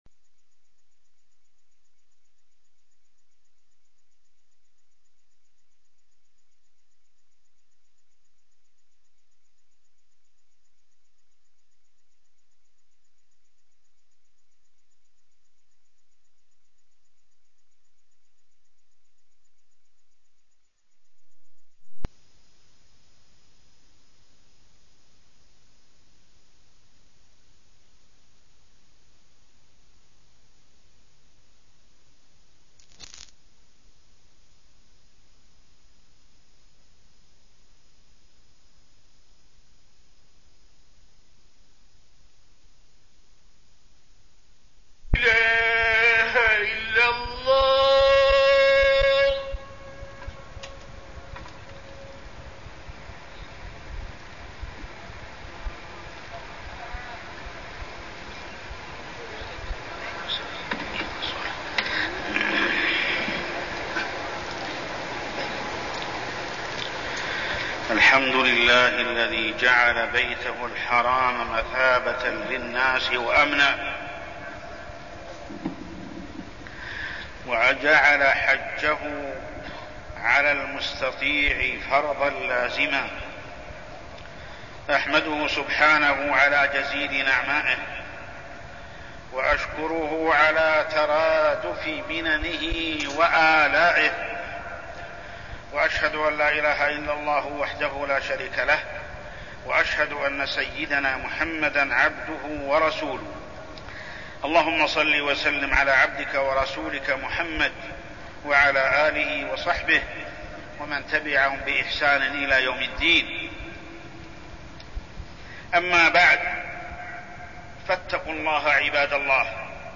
تاريخ النشر ٢٨ ذو القعدة ١٤١٥ هـ المكان: المسجد الحرام الشيخ: محمد بن عبد الله السبيل محمد بن عبد الله السبيل فريضة الحج